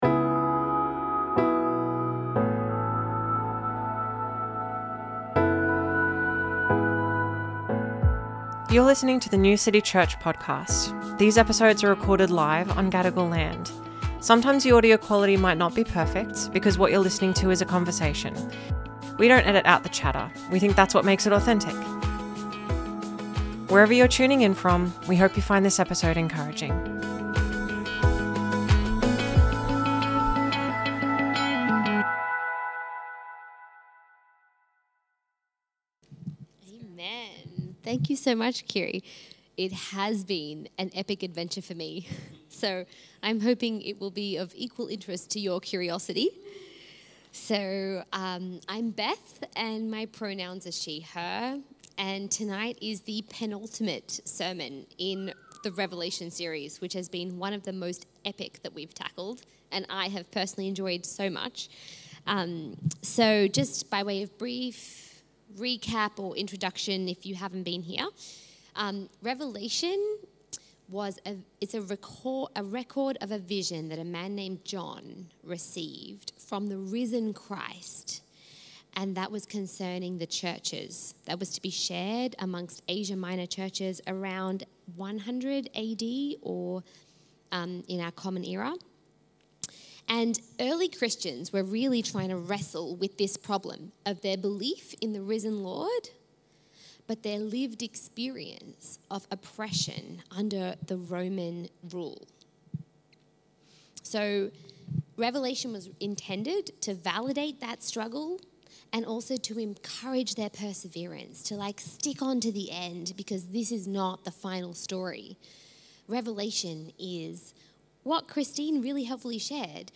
Sermons | New City Church
Guest Speaker